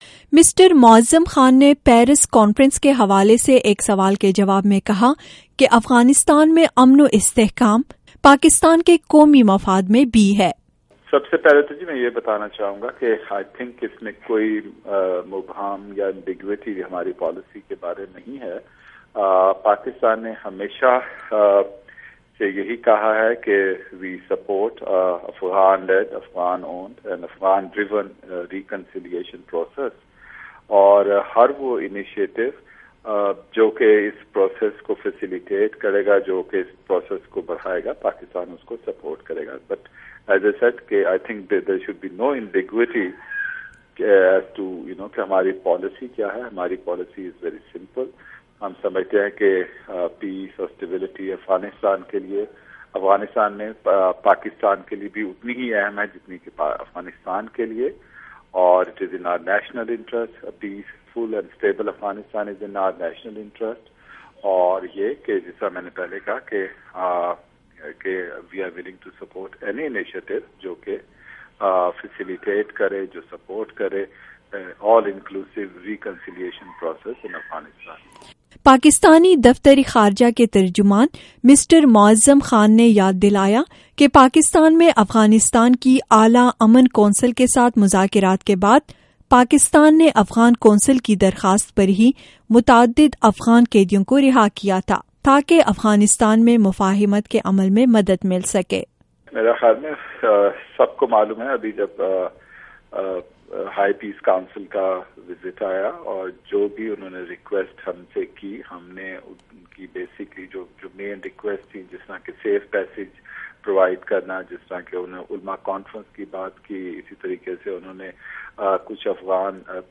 پاکستانی دفتر خارجہ کے ترجمان کی بات چیت